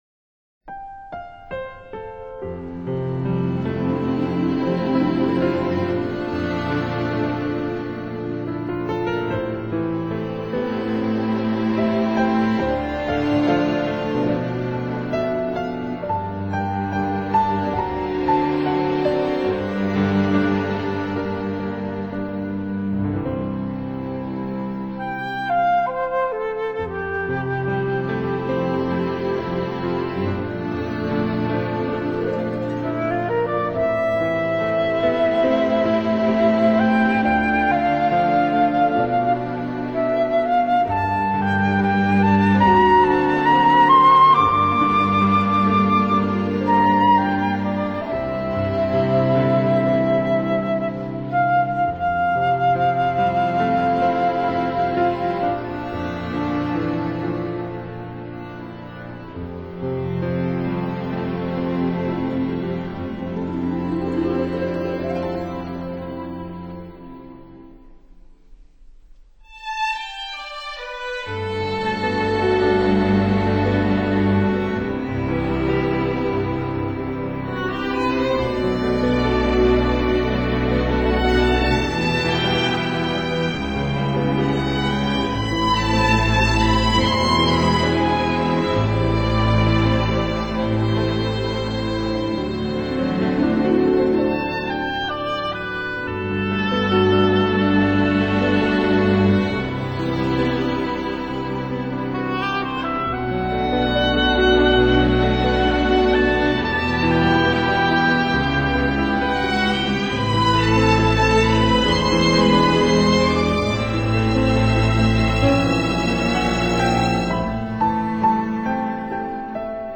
[2005-10-29]钢琴曲 《白夜》